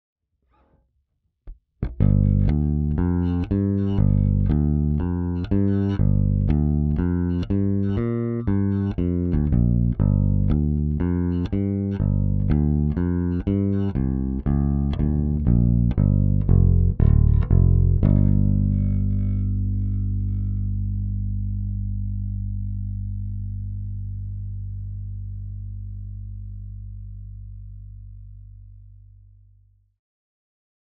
Der DI-Out per XLR ist etwas heißer und hat mehr Lautstärke, wirkt im ersten Moment also dynamischer und detailreicher.
Zweimal die selbe Bassline in der tiefen Lage mit meinem Knut 5er, einmal DI-Out, eimal Amp Out.
Ja genau, den gleichen Riff 2 mal mit Kabel umstecken und neu einpegeln.